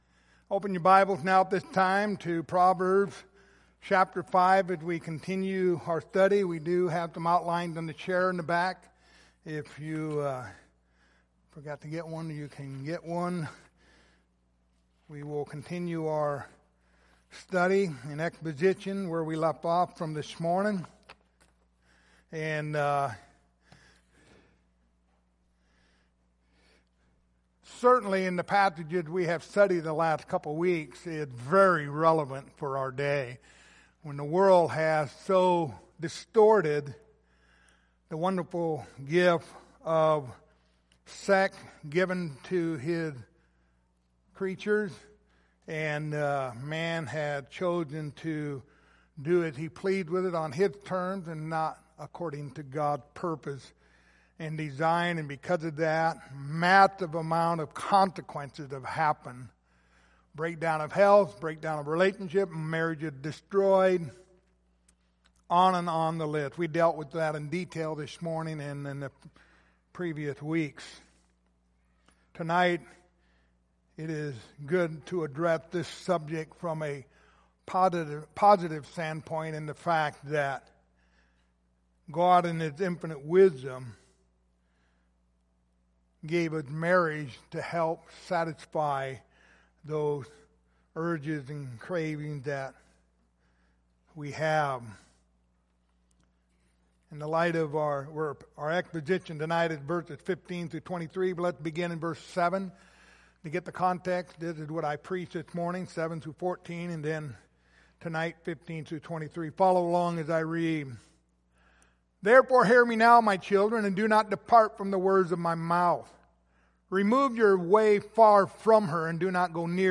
Passage: Proverbs 5:15-23 Service Type: Sunday Evening